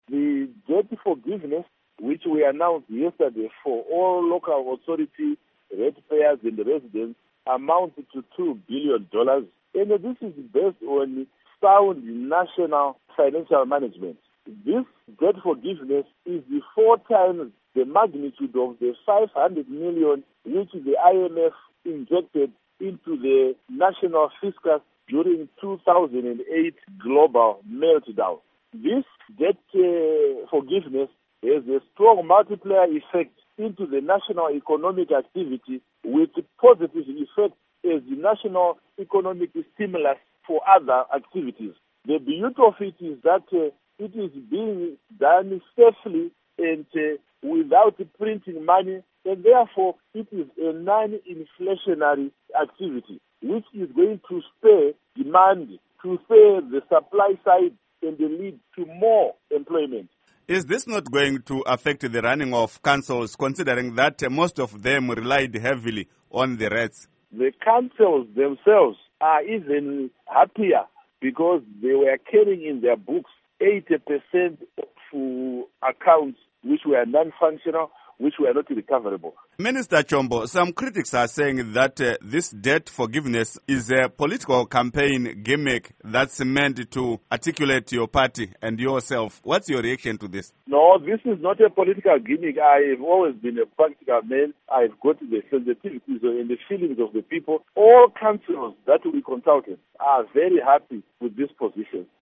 Interview With Ignatius Chombo